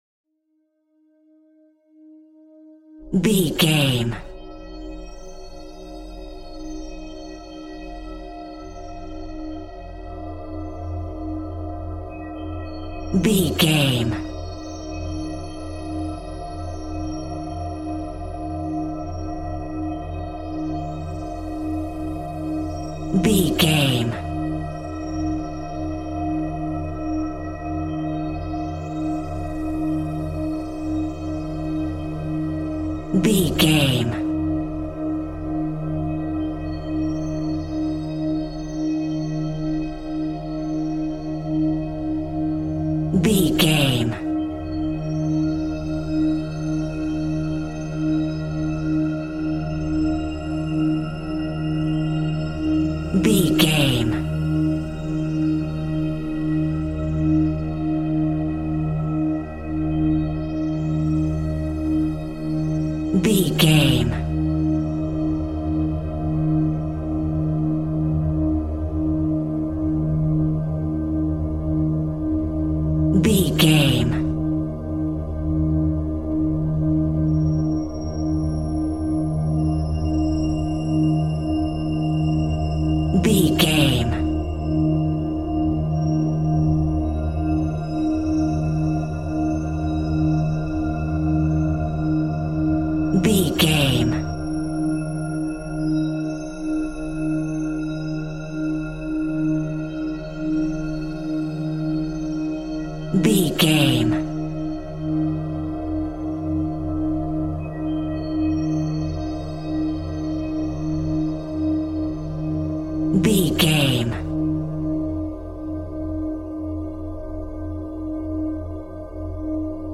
Aeolian/Minor
scary
tension
ominous
dark
suspense
eerie
synthesizer
horror
Synth Pads
atmospheres